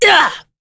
Morrah-Vox_Attack4.wav